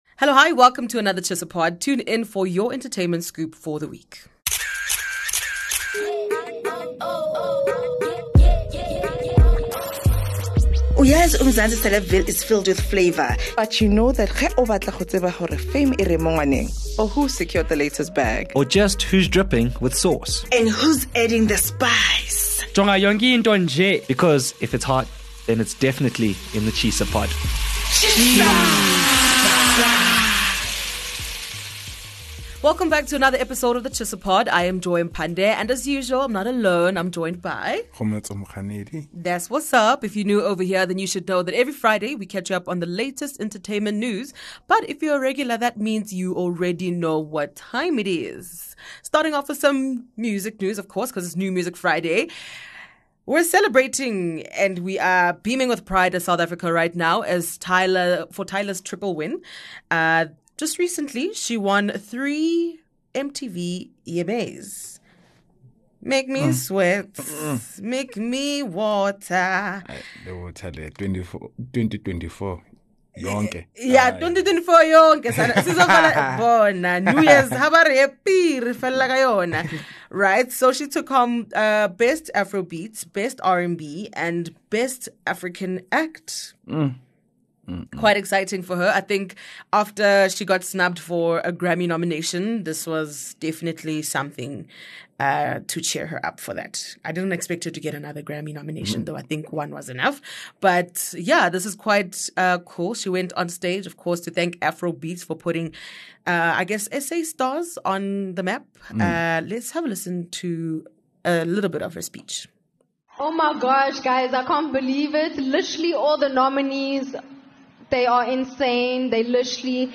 1 From Sello Maake kaNcube's 'hellish' marriage to Da L.E.S' health update — top stories of the week 27:00 Play Pause 1d ago 27:00 Play Pause Redă mai târziu Redă mai târziu Liste Like Plăcut 27:00 Catch up on your top entertainment headlines of the week, adding exciting commentary from entertainment journalists and voices from your faves.